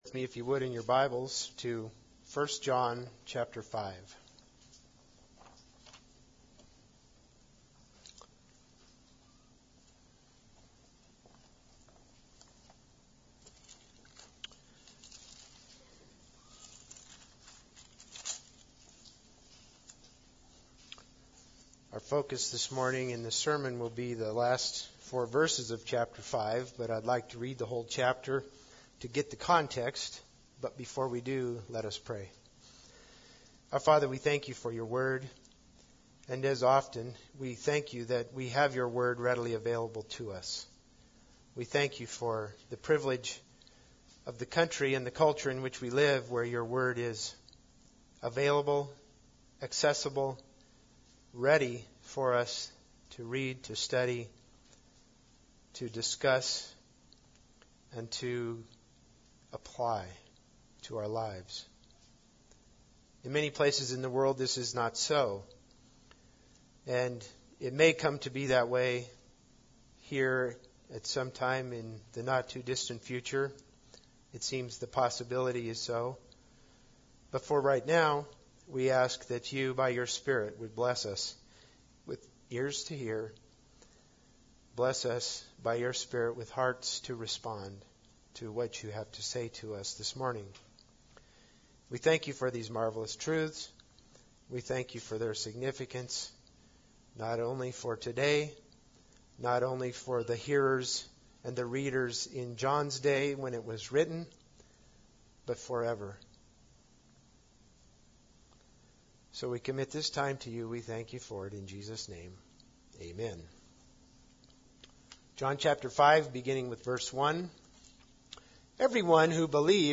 1 John 5:18-21 Service Type: Sunday Service Bible Text